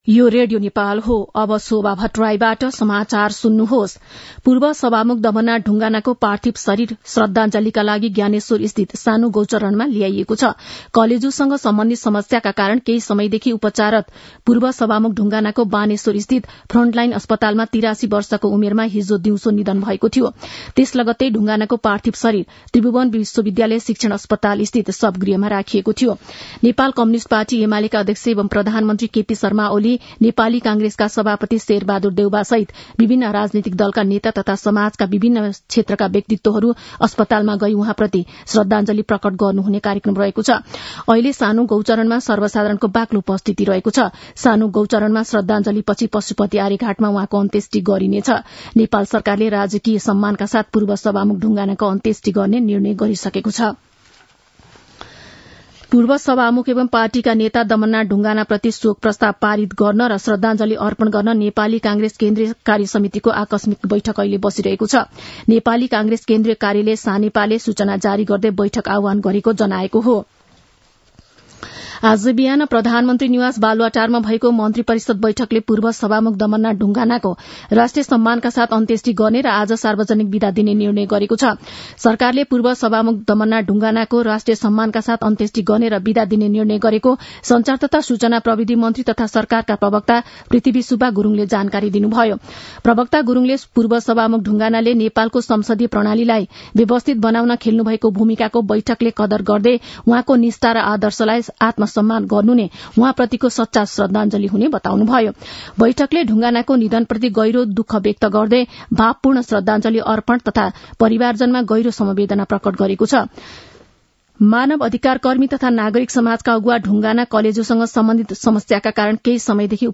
मध्यान्ह १२ बजेको नेपाली समाचार : ४ मंसिर , २०८१
12-am-nepali-news-1-2.mp3